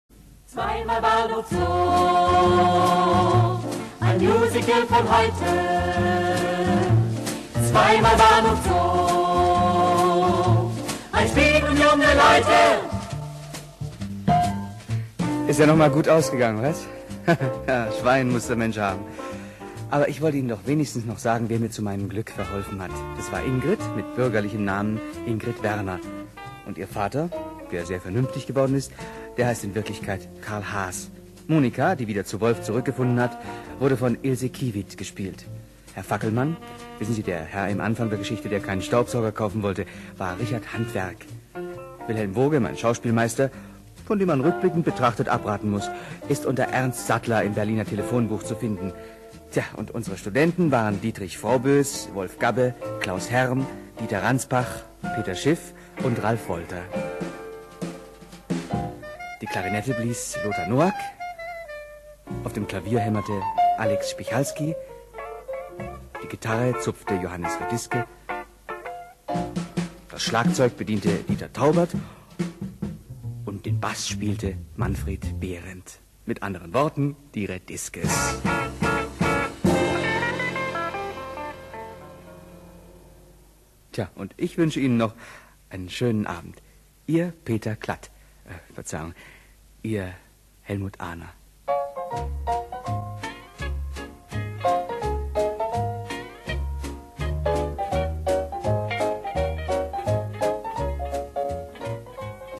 Rundfunkmusical (Hörspiel)